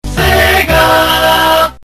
8d82b5_sonic_sega_sound_effect.mp3